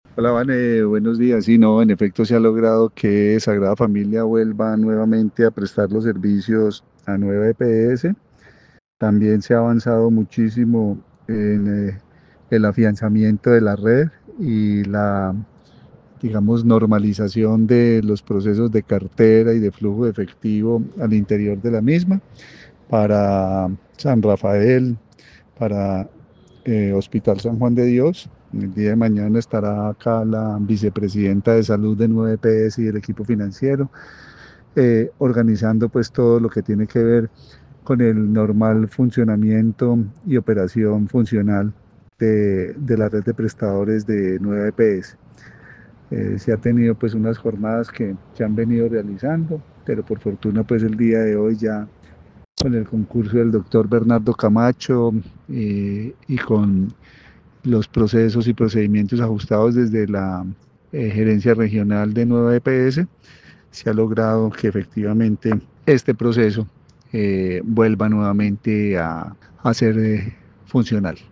Carlos Alberto Gómez, secretario de Salud del Quindío